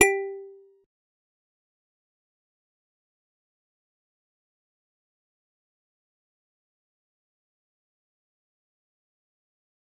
G_Musicbox-G4-pp.wav